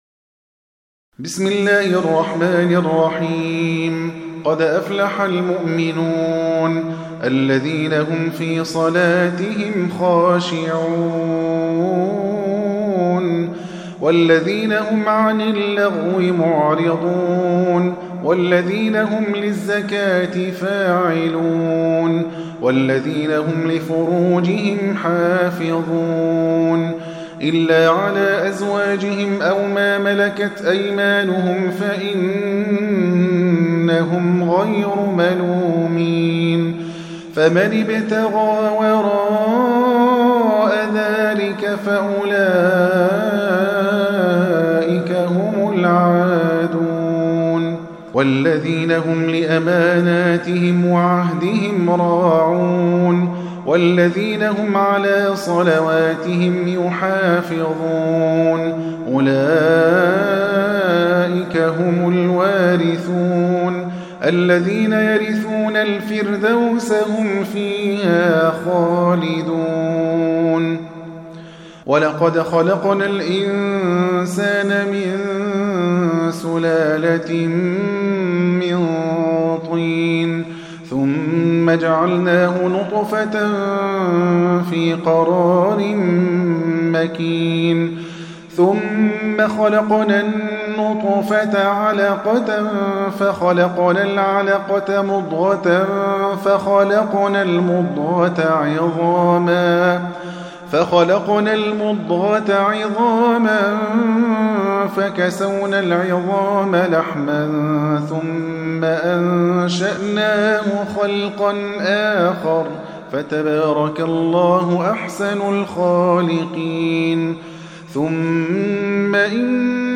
23. Surah Al-Mu'min�n سورة المؤمنون Audio Quran Tarteel Recitation
Surah Sequence تتابع السورة Download Surah حمّل السورة Reciting Murattalah Audio for 23.